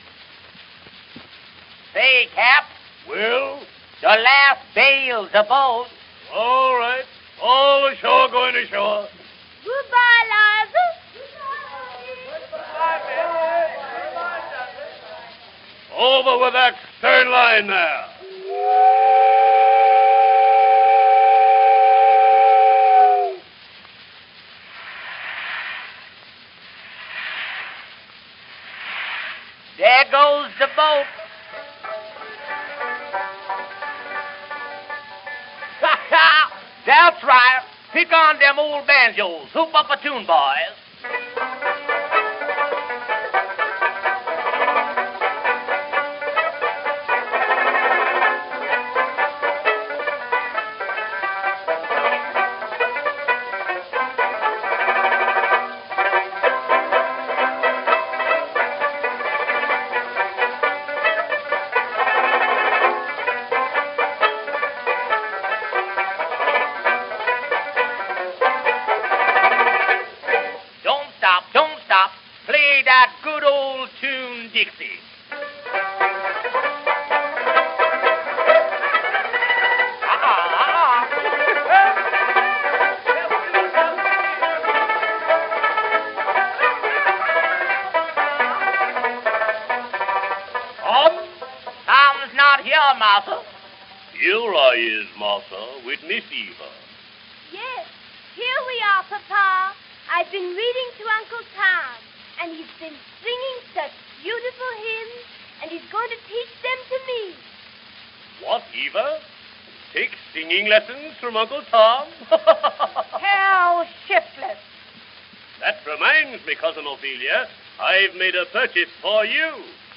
Depite the scratchiness of this recording, it's a very eloquent means of access to the way Uncle Tom's Cabin was heard by at least a generation of American audiences.
It was recorded in November, 1910, on one of Edison's "Four Minute" Ambersol Cylinders.
Spencer almost certainly enacts all the male voices, and it's possible that a single woman does Ophelia, Eva and Topsy. Within its four minute limits the program contains a mix of travelogue (the sounds of a steamboat) and musical variety show (with clear minstrel show origins) along with a gesture toward the sentimental and religious elements in Stowe's story (Eva and Tom).